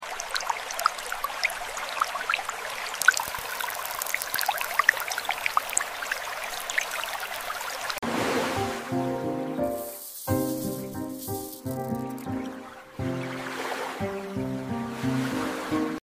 Super smooth and mesmerizing water